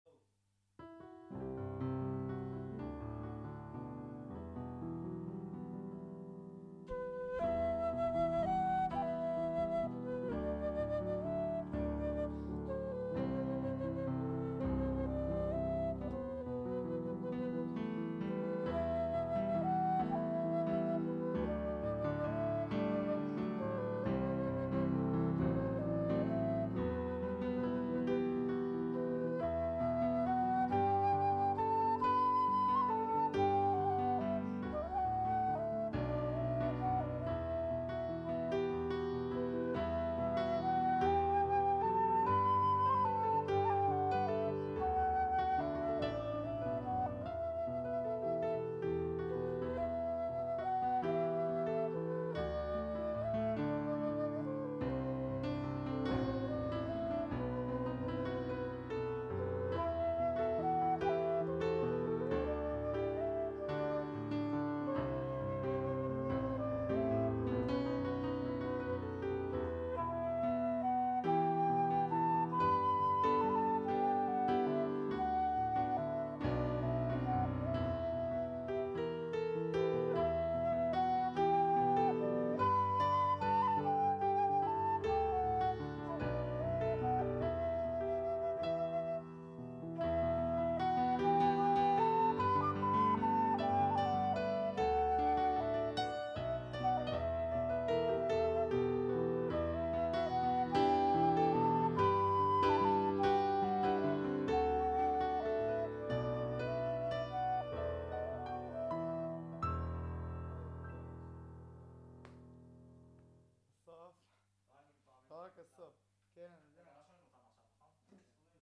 25.9.2011 קלידים: אני חליל
[הדיבורים בסוף.. כדאי מאוד מאוד לחתוך אותם.]